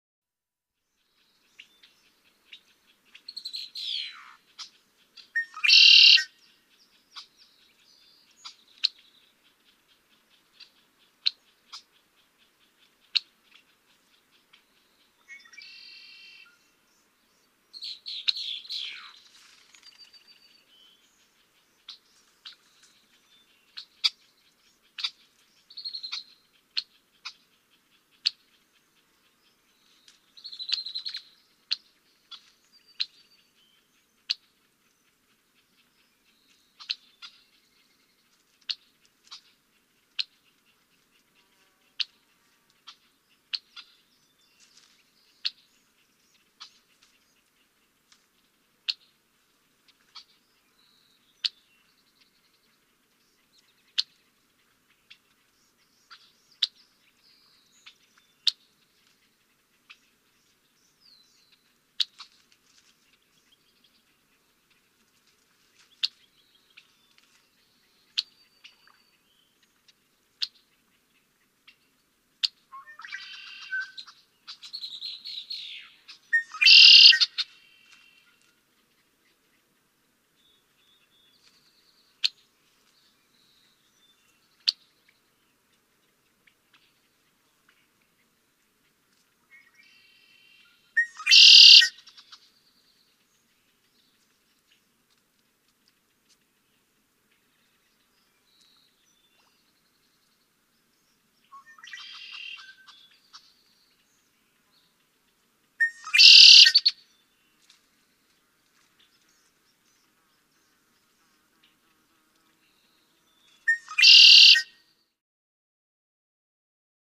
Blackbird, Red-winged Chirps. Short, Click-like Chirps And Warbles With Some Wing Flutter. Bird